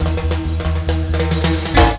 FILLTIMB03-R.wav